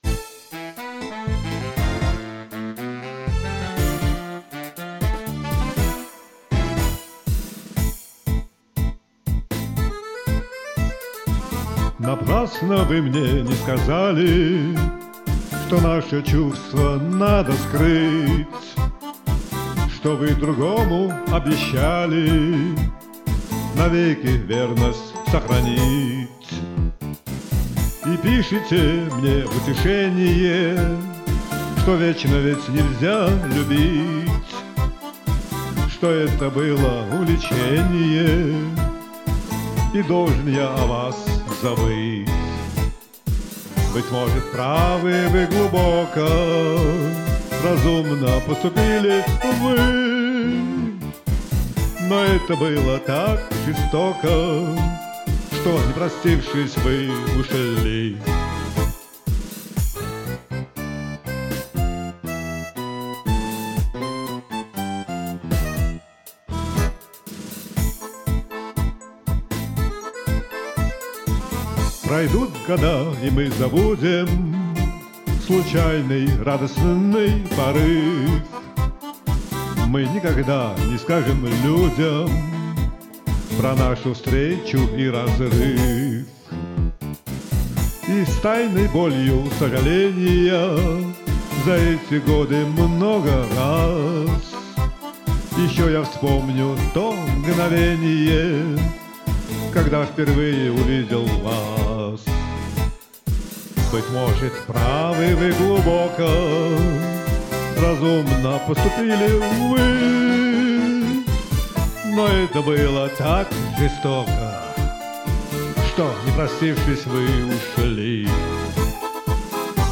несколько рванное со сбитым ритмом исполнение